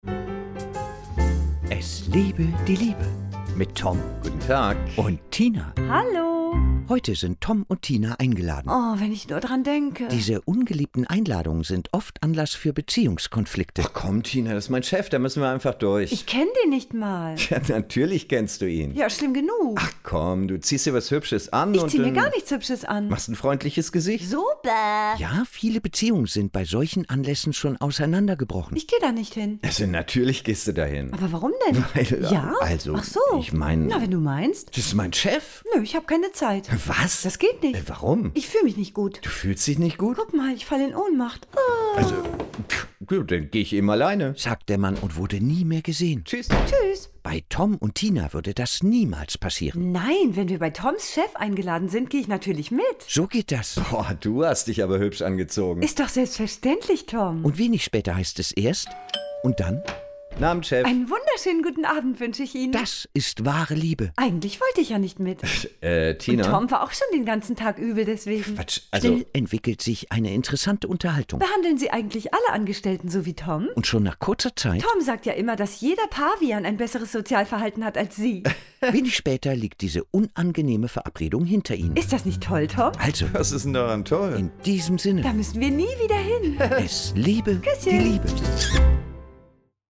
Hörfunkserie
Die Radio-Comedy bekannt vom WDR, NDR, SWR und HR.